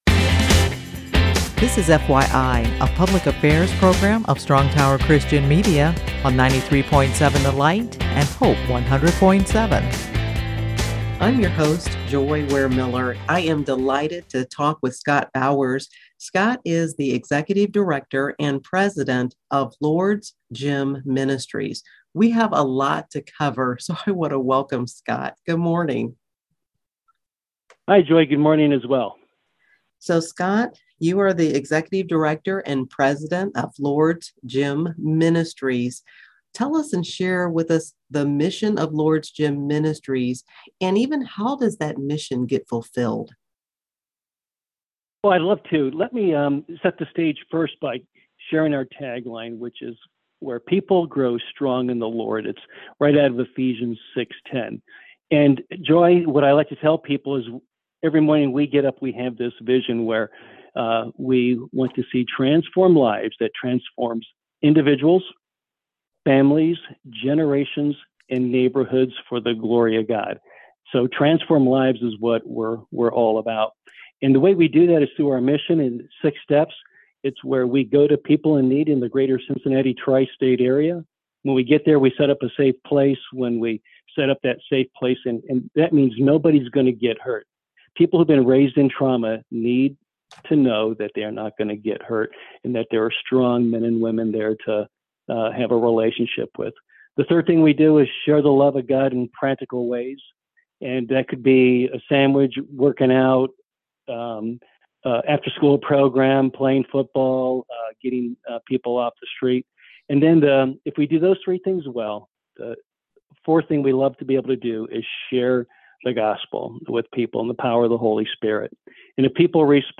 Interview with Strong Tower Christian Media